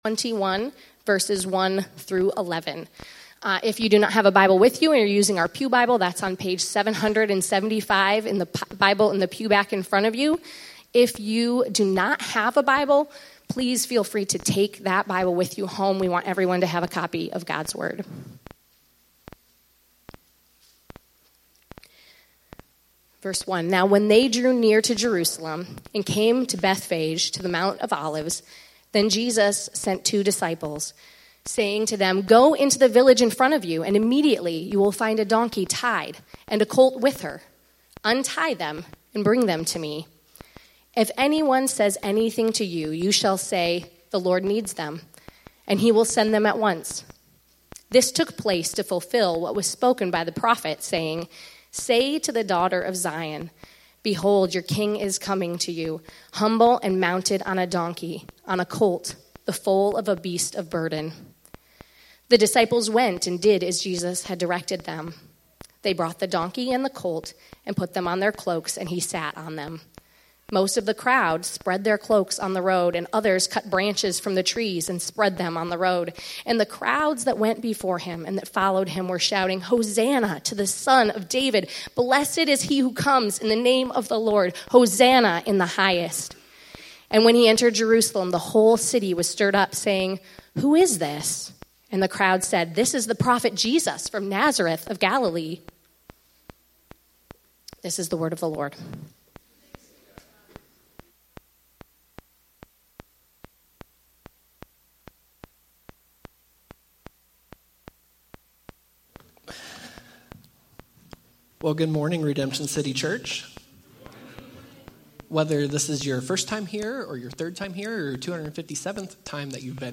Sermons by Redemption City Church